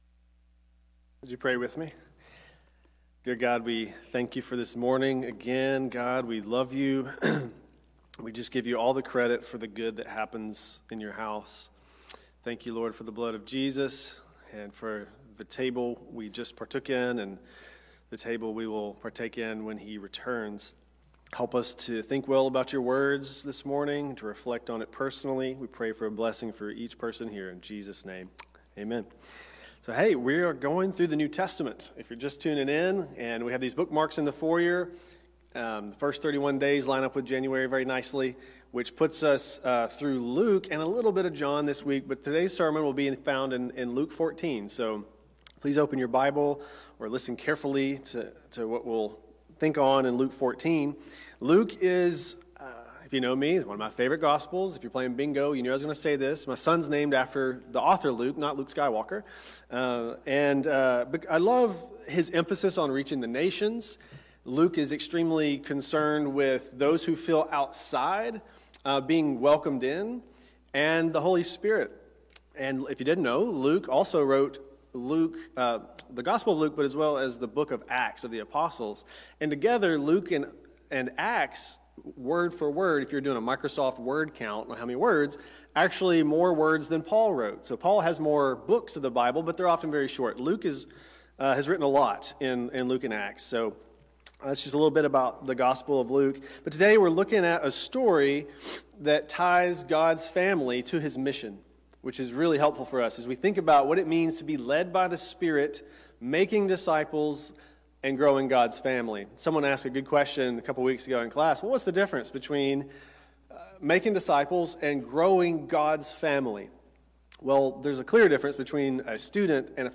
Weekly Sermon Audio